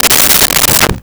Dresser Drawer Closed 04
Dresser Drawer Closed 04.wav